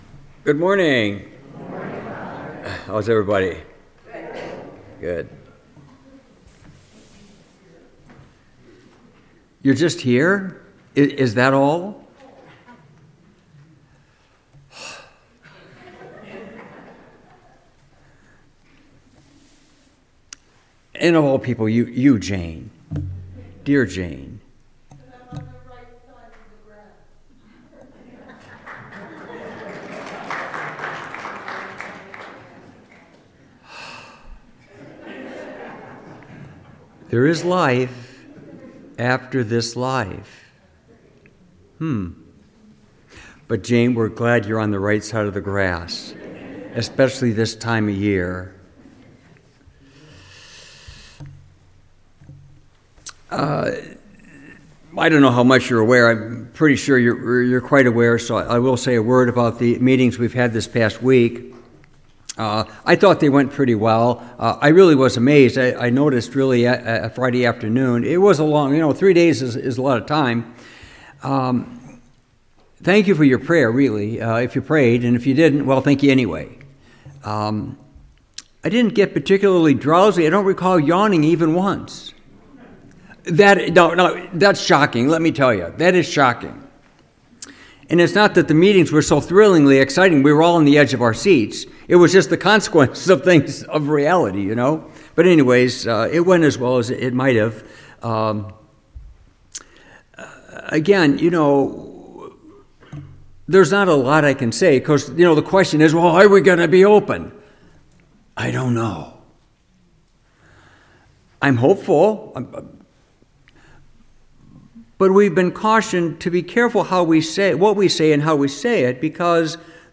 Homily – January 18, 2026